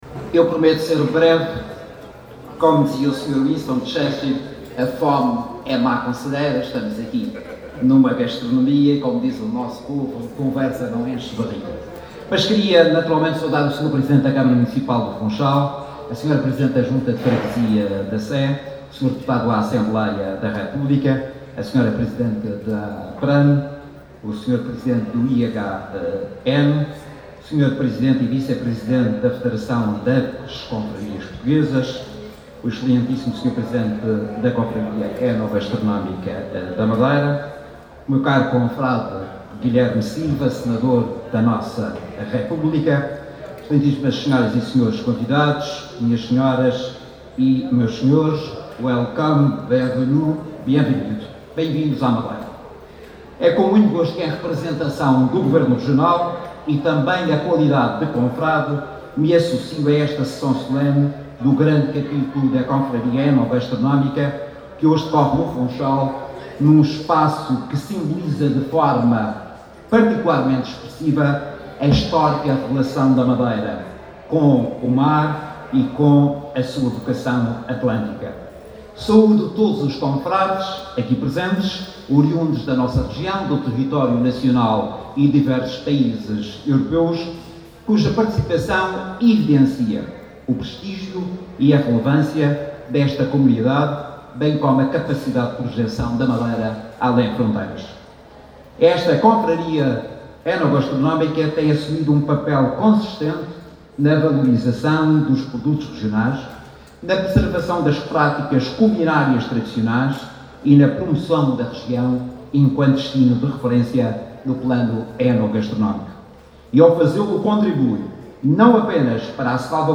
O Secretário Regional da Economia sublinhou, hoje, a importância crescente da gastronomia enquanto fator de valorização económica e turística da Região, durante a sessão solene do XXVI Grande Capítulo da Confraria Enogastronómica da Madeira, que decorreu na Gare Marítima do Porto do Funchal.